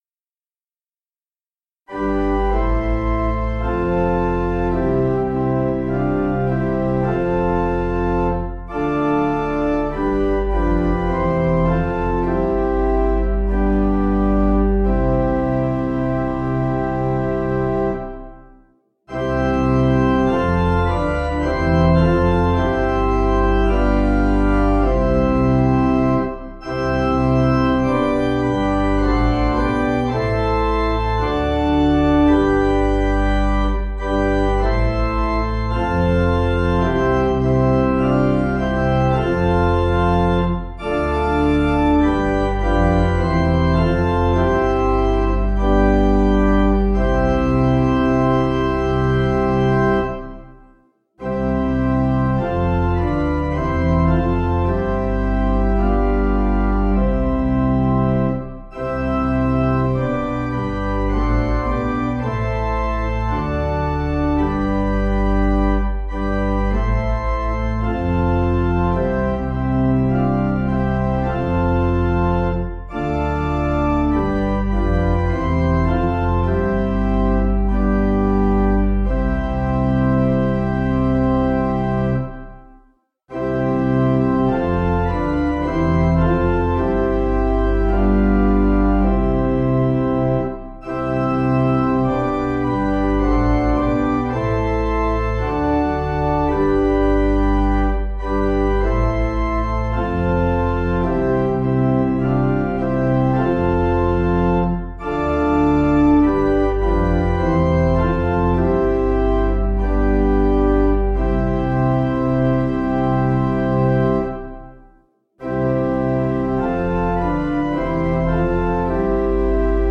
Piano/Organ